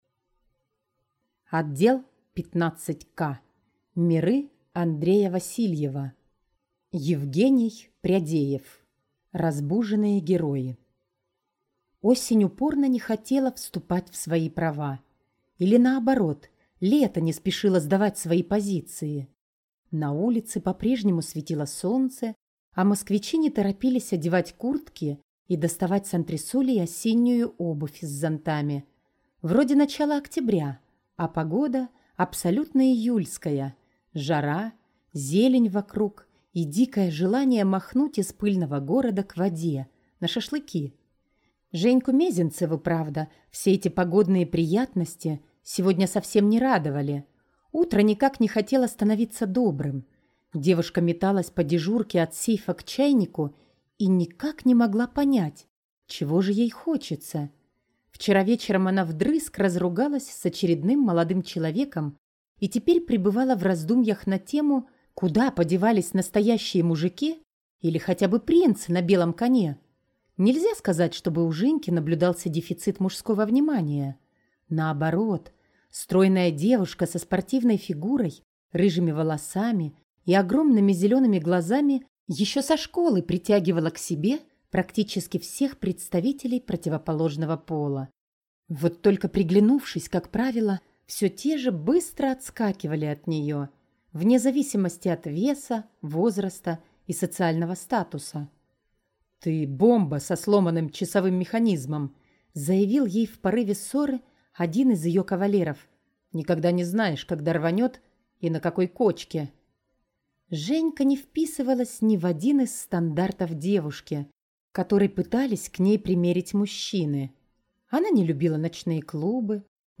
Аудиокнига Разбуженные герои | Библиотека аудиокниг